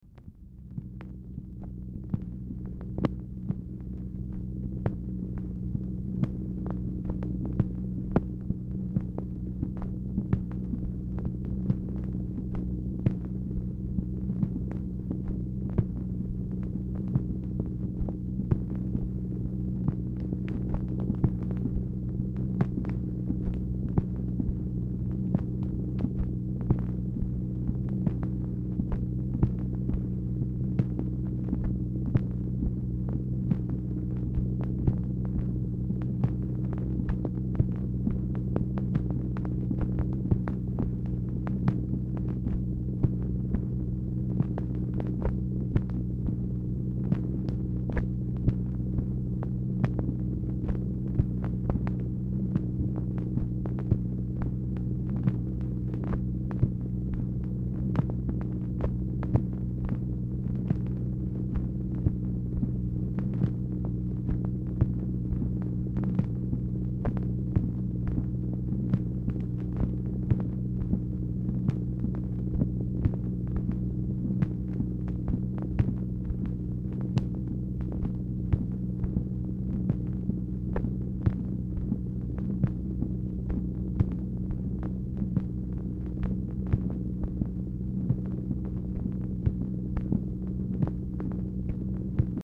MACHINE NOISE
Oval Office or unknown location
Telephone conversation
Dictation belt